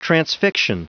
Prononciation du mot transfixion en anglais (fichier audio)